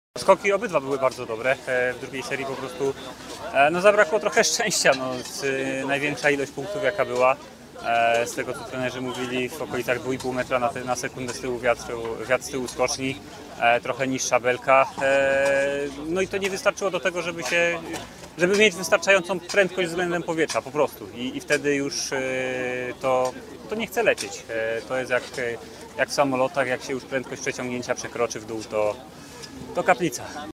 Dawid-Kubacki-po-konkursie-indywidualnym.mp3